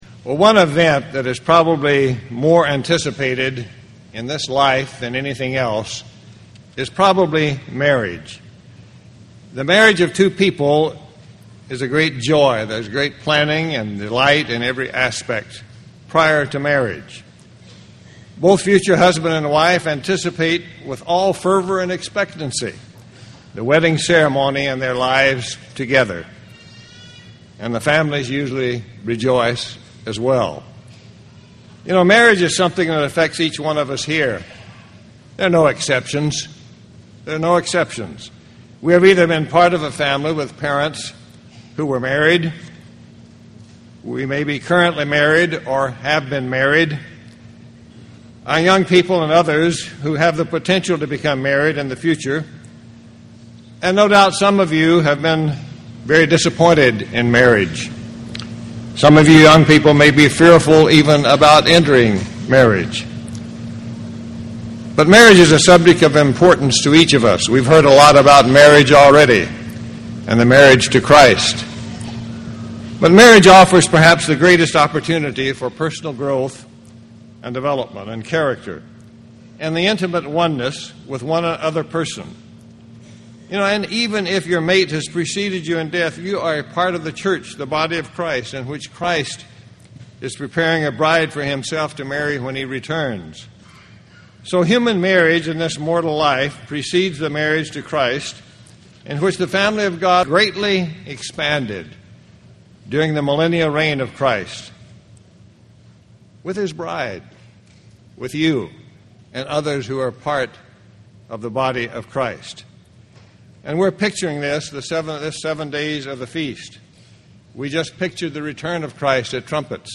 This sermon was given at the Wisconsin Dells, Wisconsin 2007 Feast site.